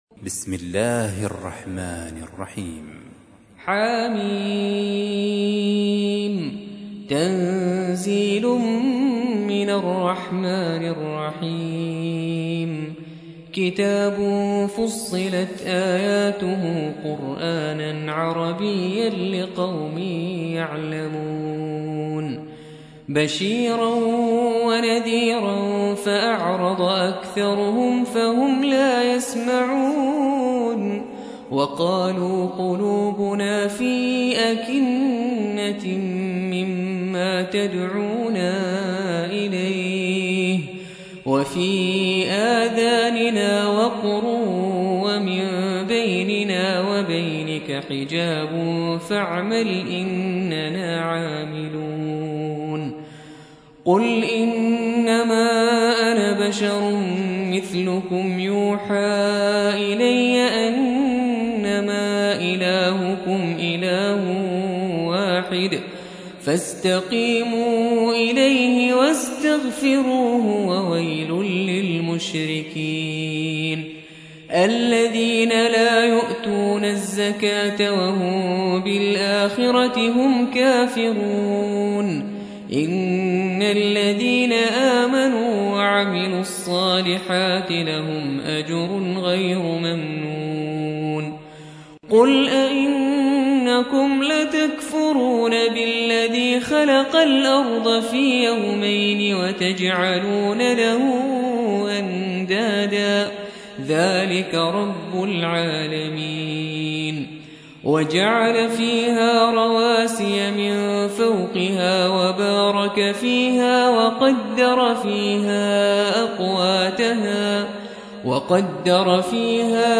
41. سورة فصلت / القارئ